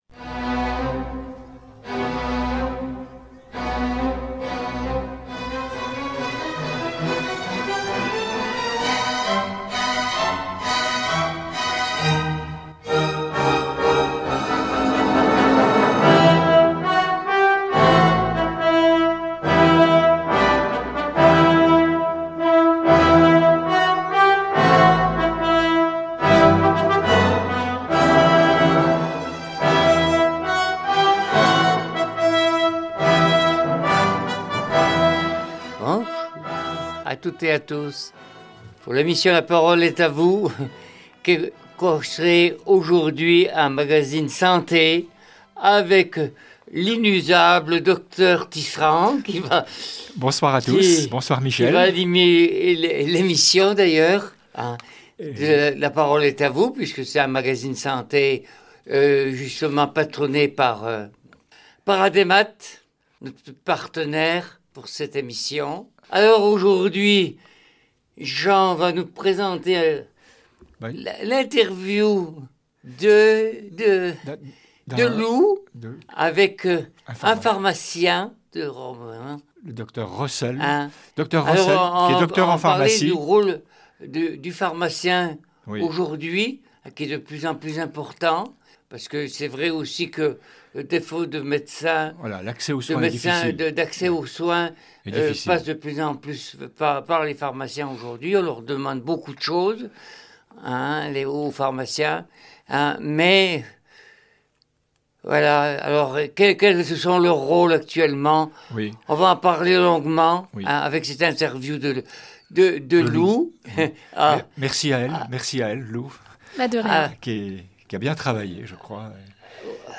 Une émission à la fois vivante et instructive pour comprendre ce qui se joue derrière le comptoir.